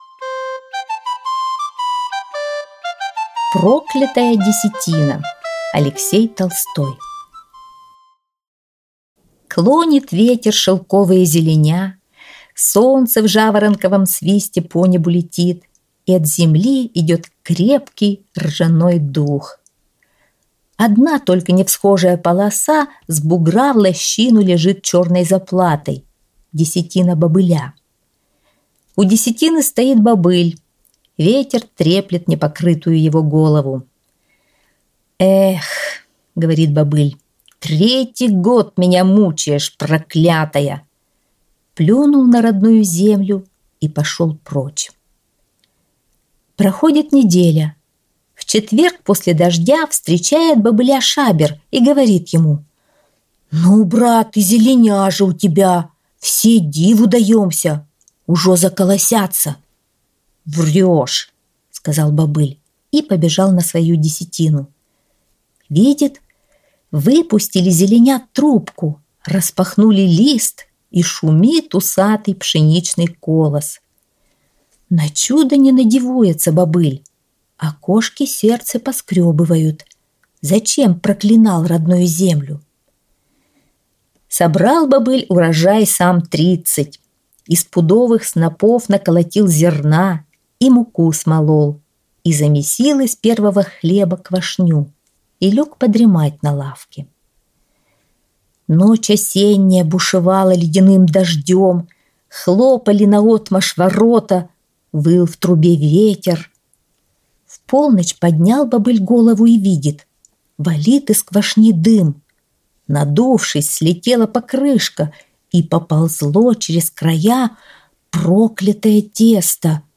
Проклятая десятина – Толстой А.Н. (аудиоверсия)
аудиосказки для 3-4-5 летдля 6-7-8 лет